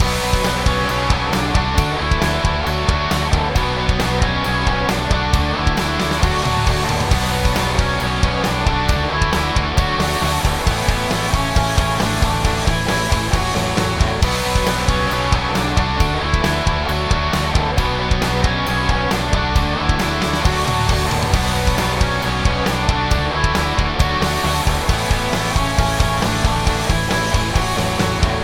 I'm gonna guess the first is the Mark V because it sounds like a Mark V to me, and the second half is the pedal direct because it actually sounds direct...